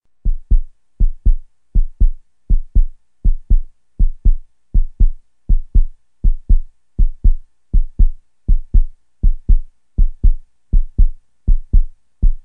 Latido_corazon.mp3